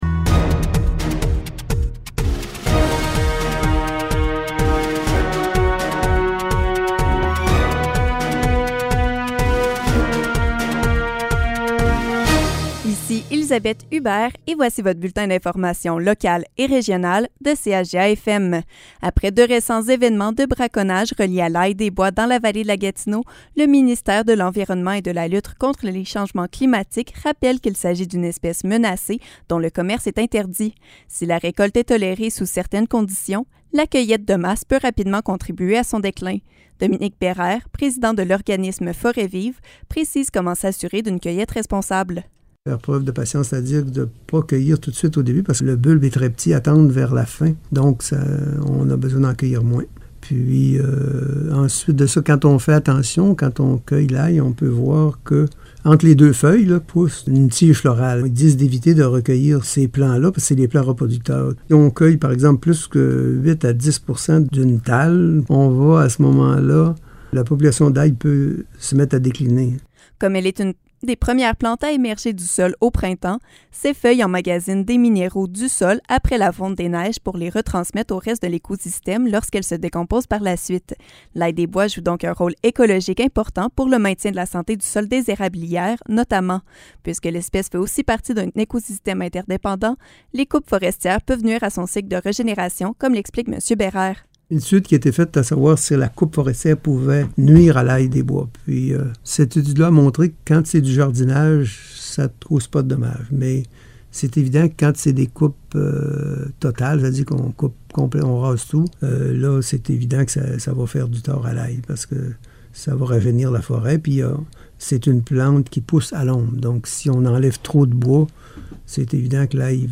Nouvelles locales - 14 juin 2021 - 12 h